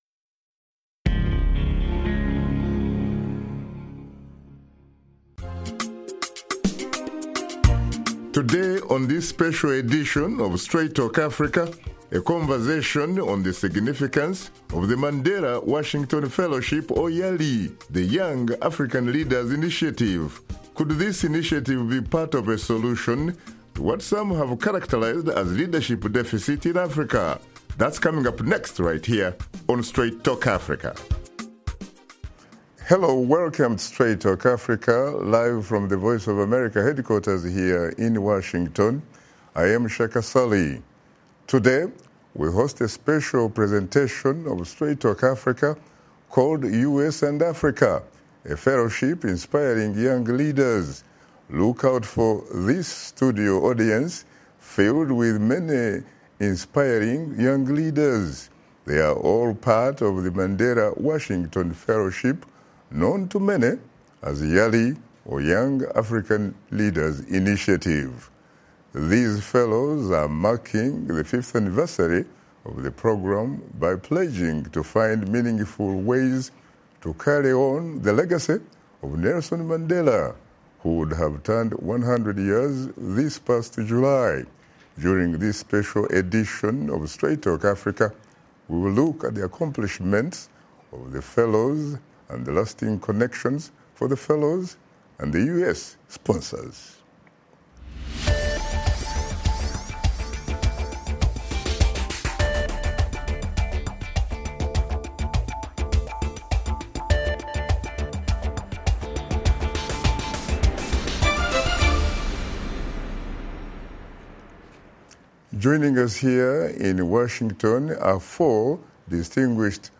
Straight Talk Africa presents a town hall introducing members of the Mandela Washington Fellows for Young African Leaders Initiative. This year, to commemorate Nelson Mandela’s 100th birthday, the fellows participated in community service projects and pledged to continue Mandela’s legacy.